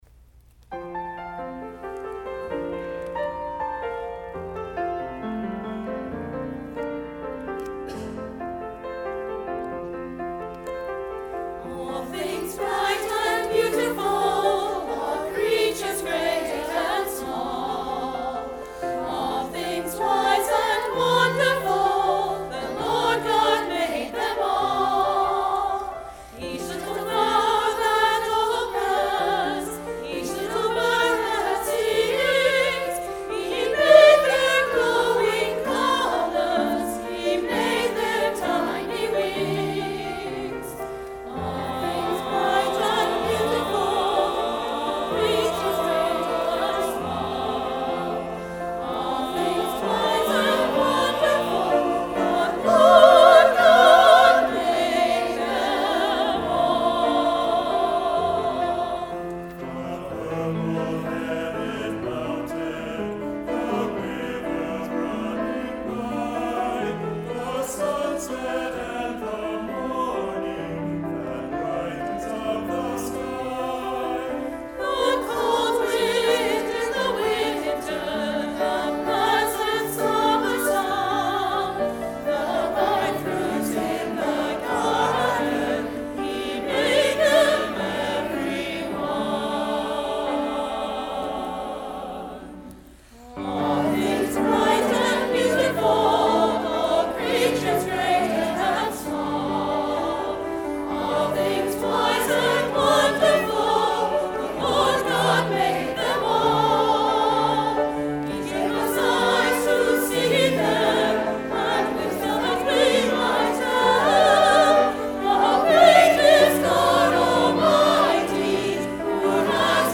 Combined Junior and Chancel Choirs
piano